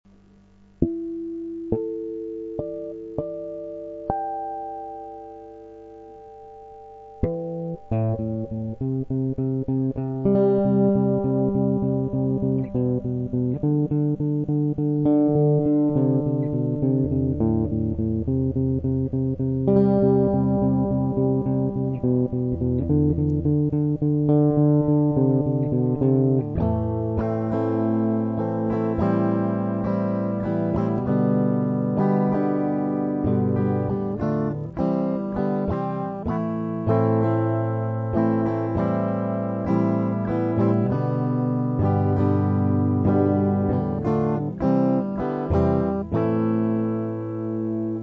mp3 - вступление и проигрыш